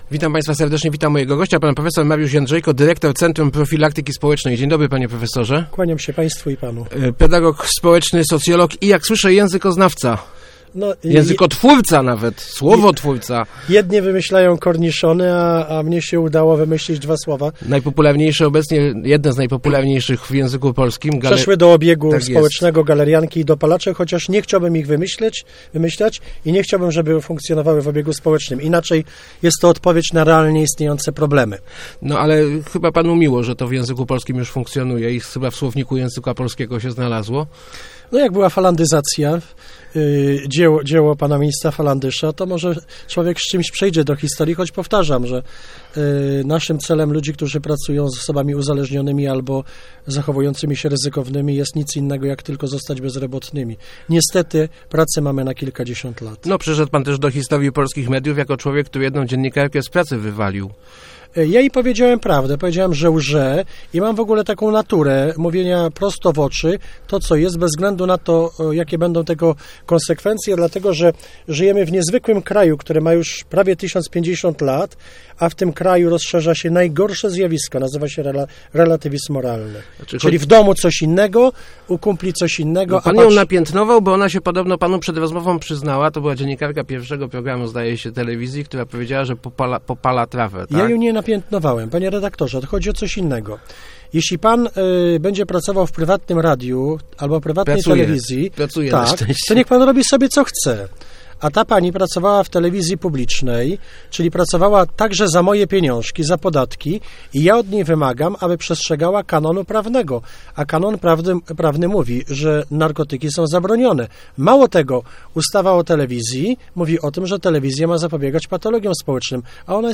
Znany i kontrowersyjny pedagog społeczny i socjolog gościł w Lesznie na zaproszenie Centrum Doskonalenia Nauczycieli. W rozmowie mówił o profilaktyce narkotykowej i wychowaniu najmłodszych.